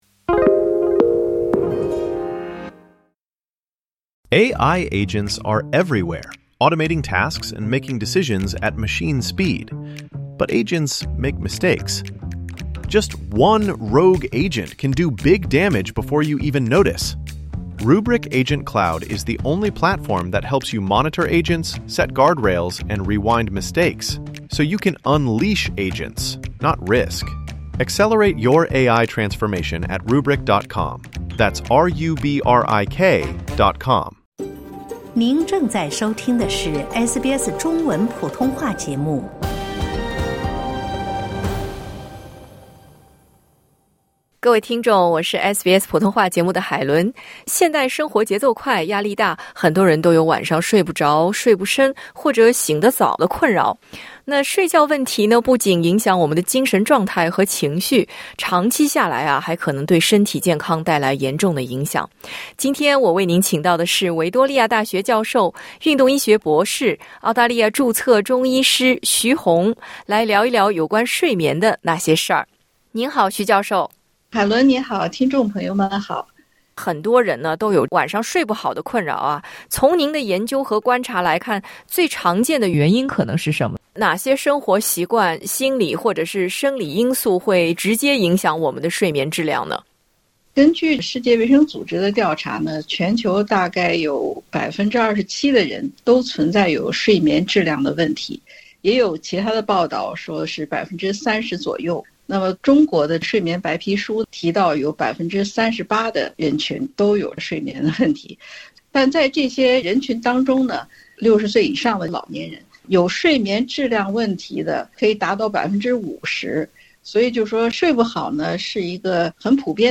如果你也正在被“睡不好”困扰，希望这个采访能帮您找到一些解法。